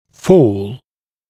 [fɔːl][фо:л]падение, понижение, спад, уменьшение, ослабление; падать, понижаться, спадать, уменьшаться, ослабевать